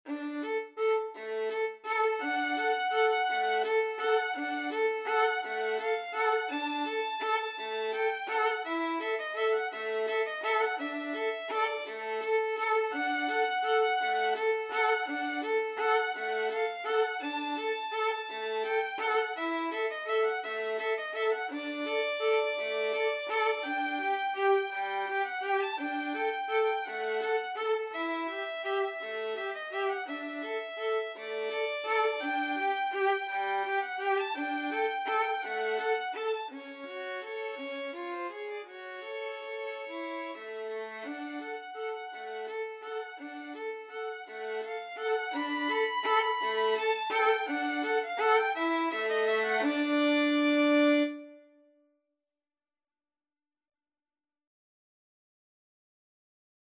Violin 1Violin 2
3/4 (View more 3/4 Music)
Tempo di valse =168
Classical (View more Classical Violin Duet Music)